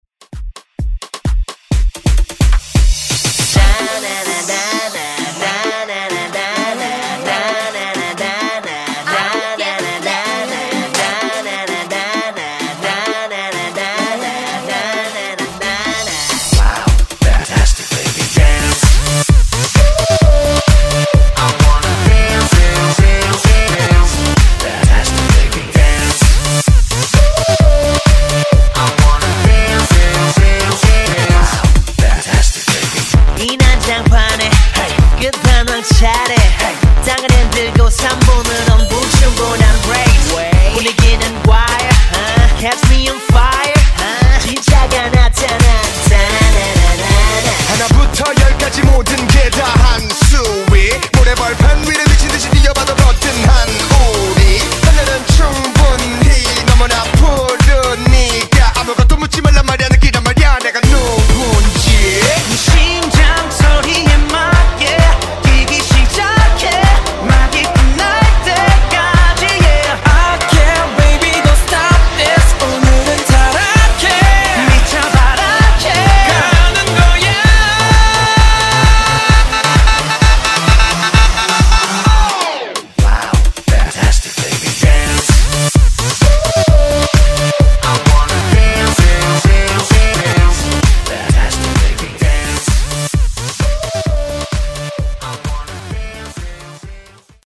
Genres: RE-DRUM , TOP40
Clean BPM: 110 Time